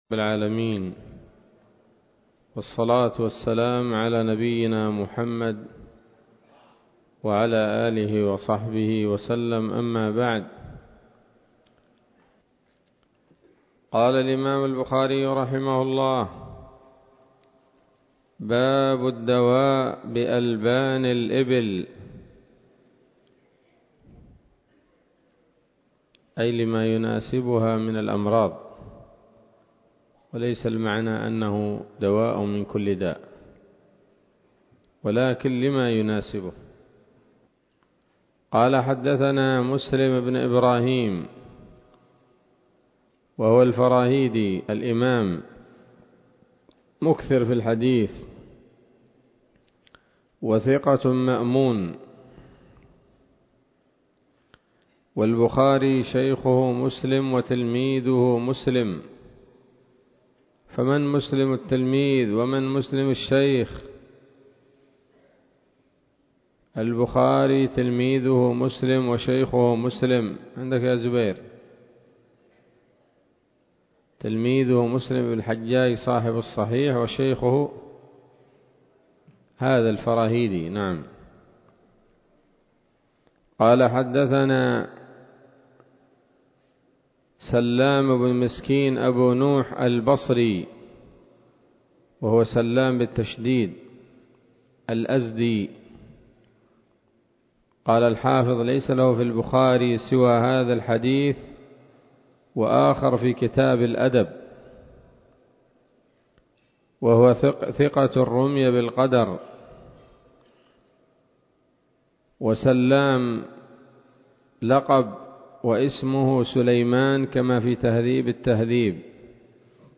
الدرس الخامس من كتاب الطب من صحيح الإمام البخاري